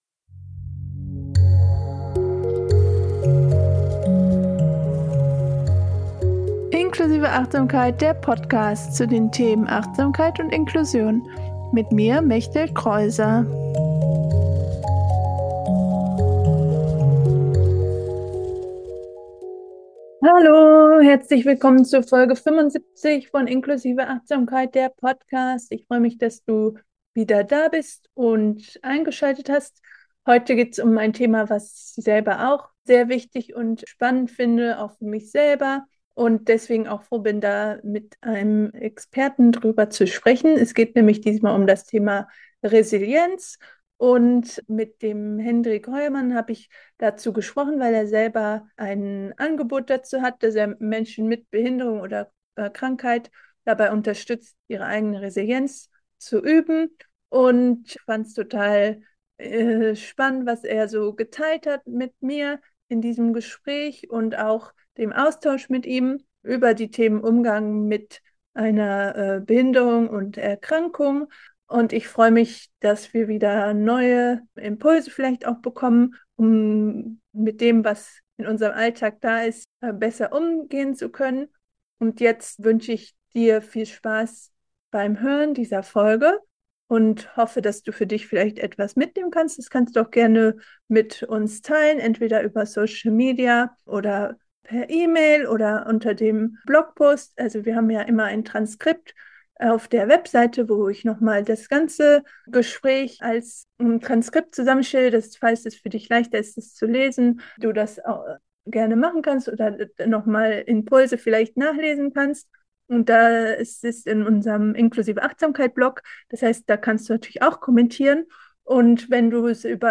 75 - Mit Resilienz Krisen bewältigen - Interview